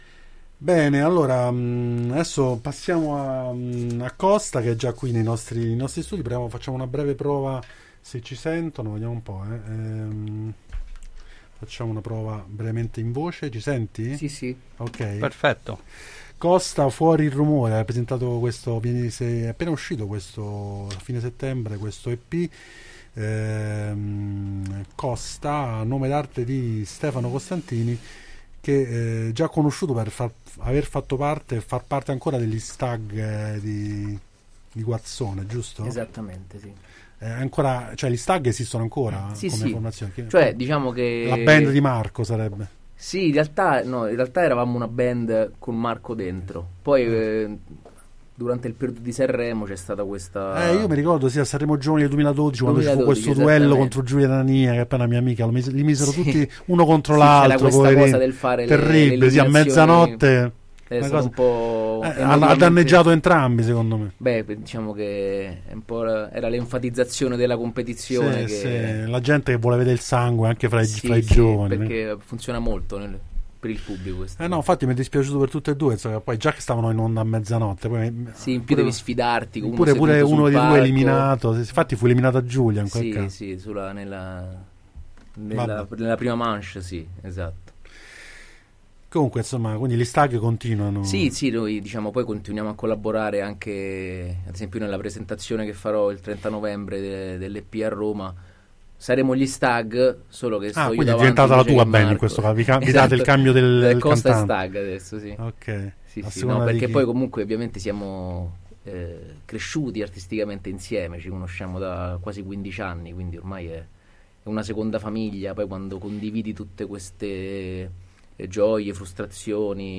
chitarra e voce
Interviste